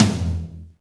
soundboard / sounds / drums